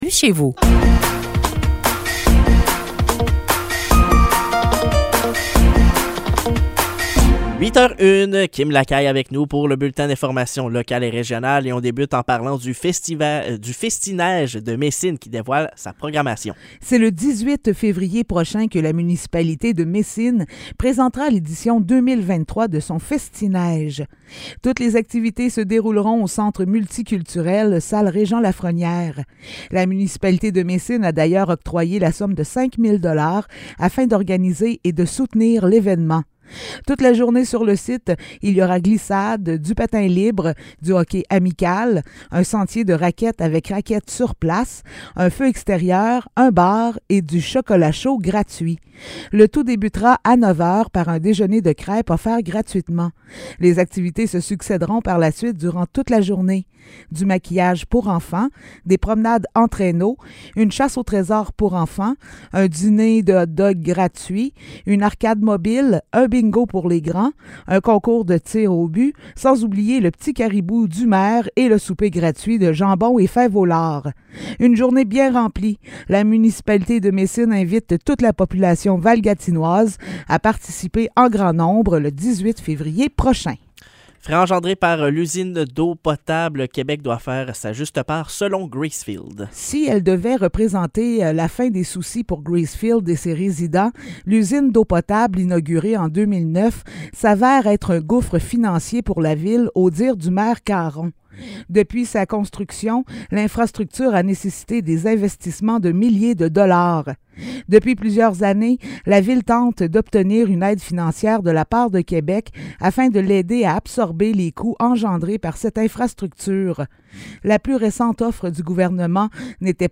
Nouvelles locales - 31 janvier 2023 - 8 h